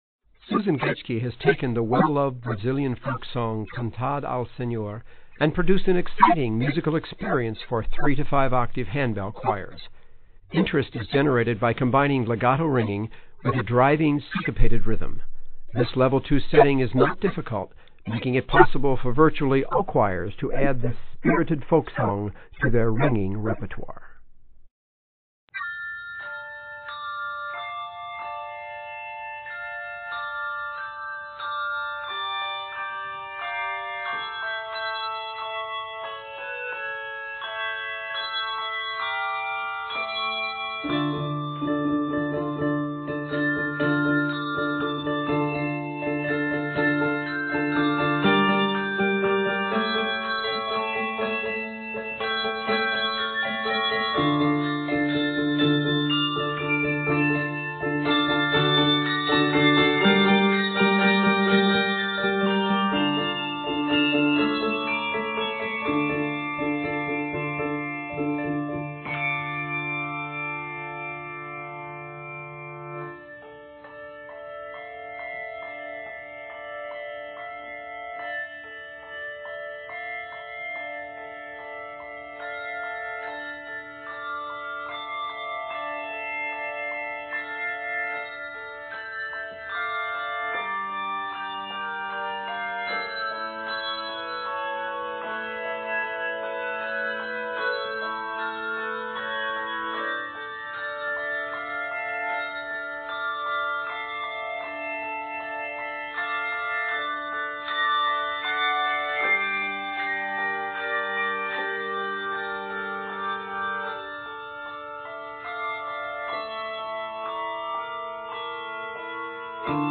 Arranged in d minor, measures total 69.